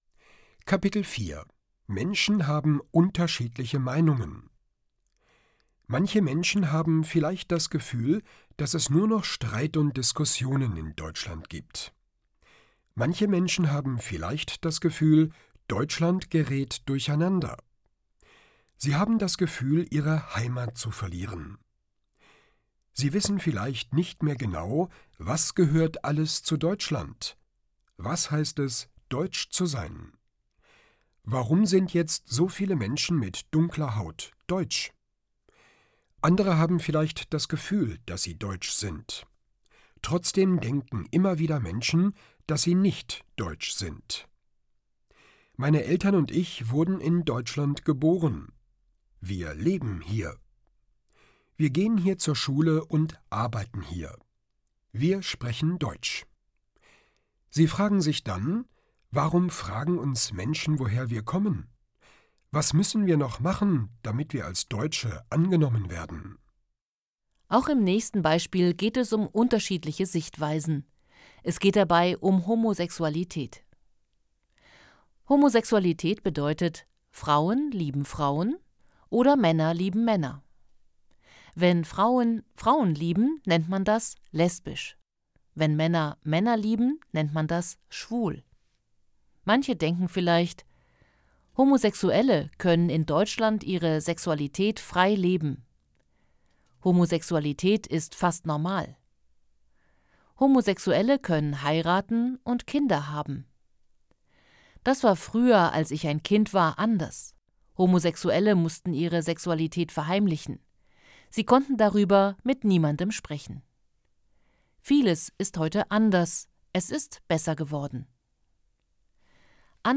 Kapitel 4: Menschen haben unterschiedliche Meinungen Hörbuch: „einfach POLITIK: Zusammenleben und Diskriminierung“
• Produktion: Studio Hannover